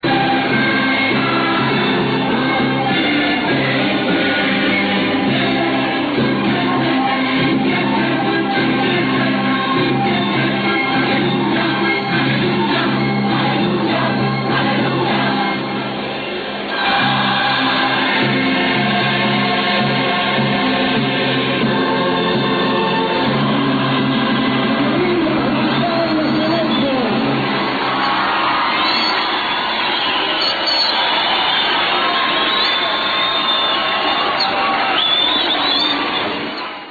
The choir cheers after singing Hallelujah, which ended the mass in Havana. Real Audio of the choir singing Hallelujah.